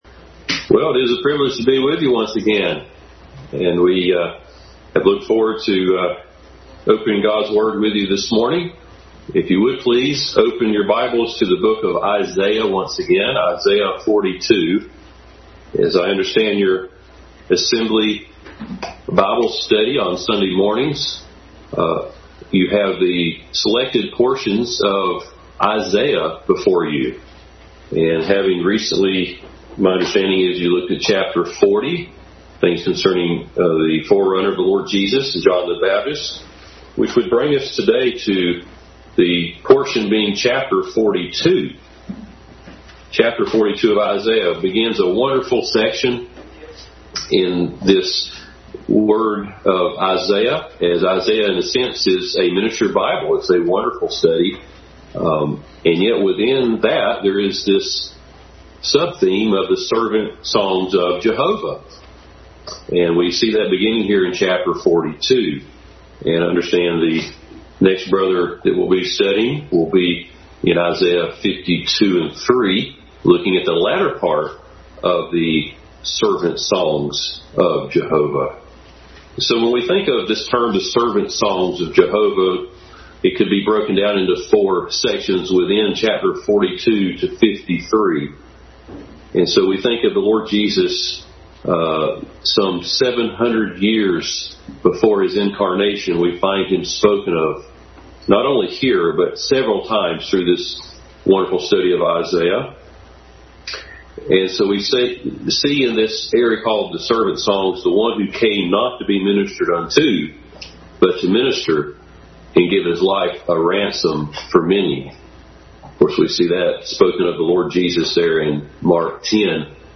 Adult Sunday School Class continued study of Christ in Isaiah.
Isaiah11:1-2 Service Type: Sunday School Adult Sunday School Class continued study of Christ in Isaiah.